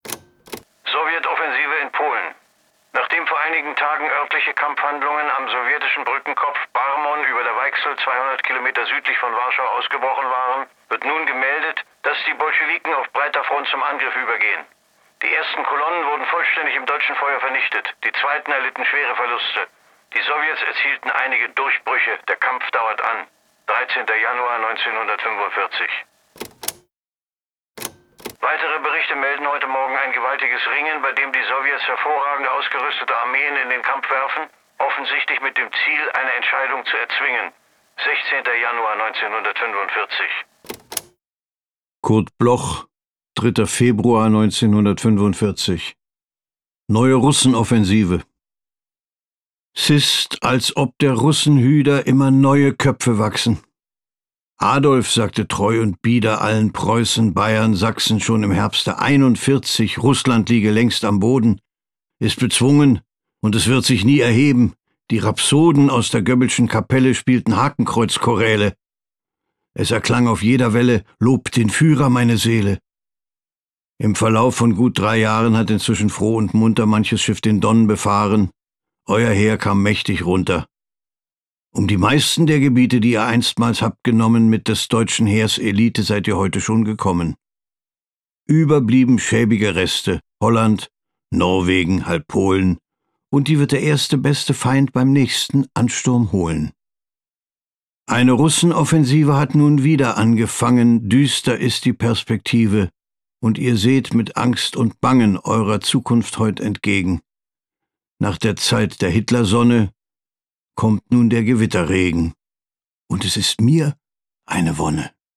performed by Burghart Klaußner